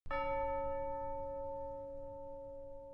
campana
Sonido FX 12 de 42
campana.mp3